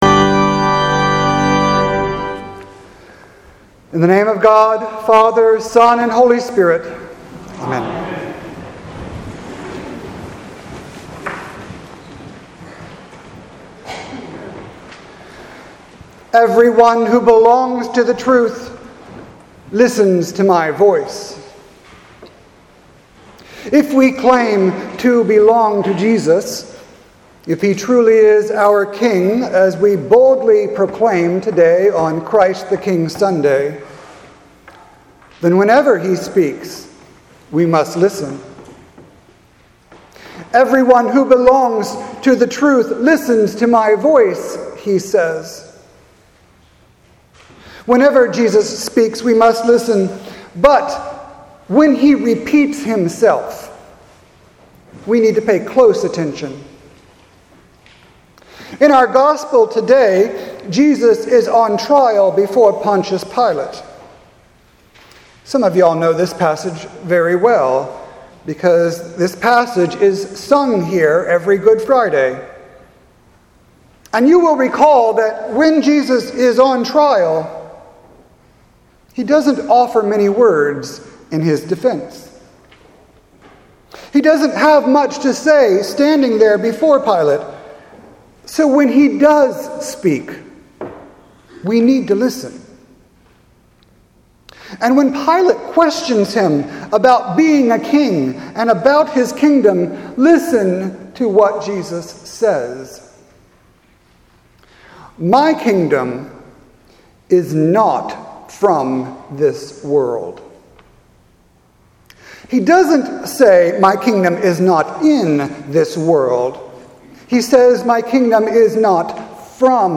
Sermon for Sunday, November 25th, 2018